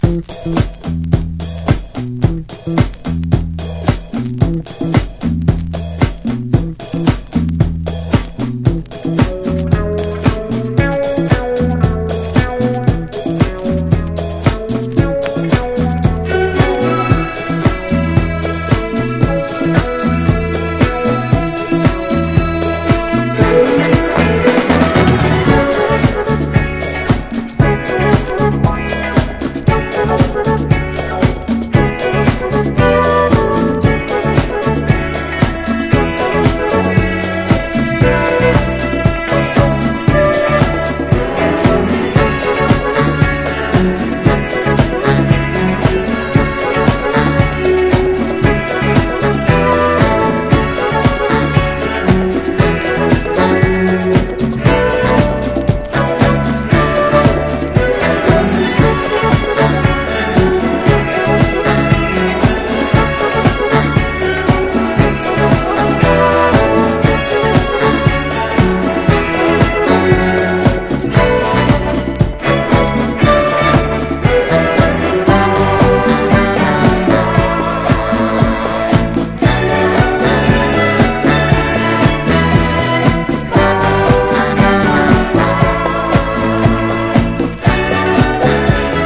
# FUNK / DEEP FUNK# SOUL 45# DISCO